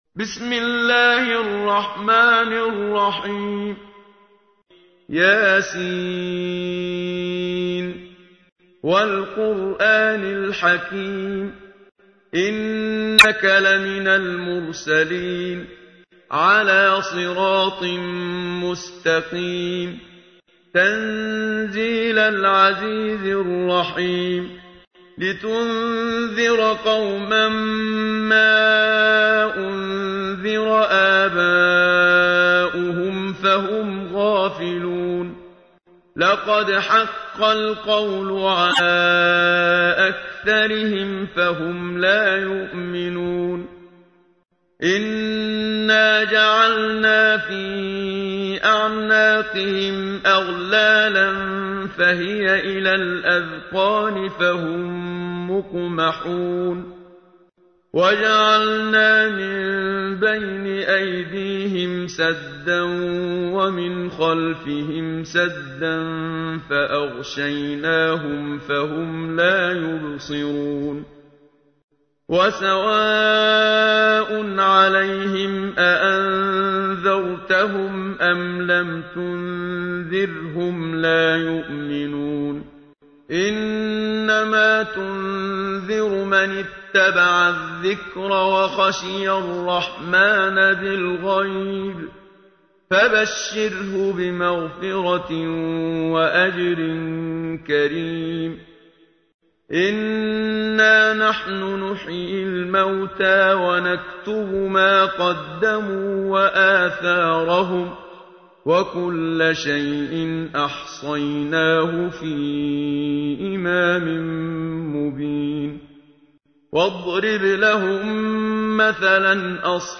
تحميل : 36. سورة يس / القارئ محمد صديق المنشاوي / القرآن الكريم / موقع يا حسين